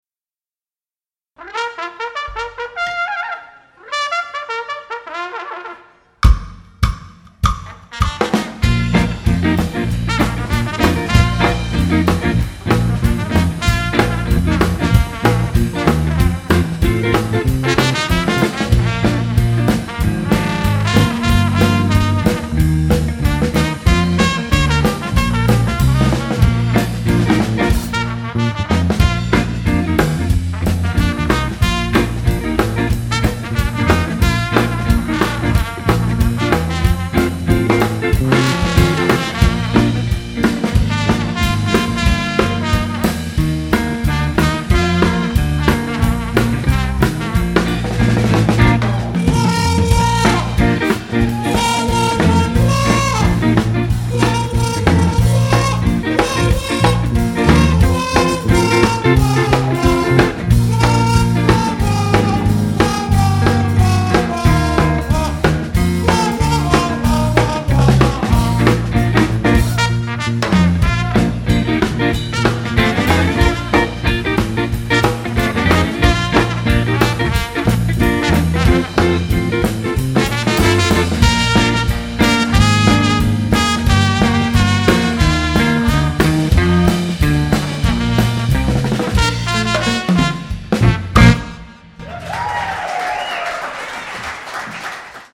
Quartet - Live at York St. Cafe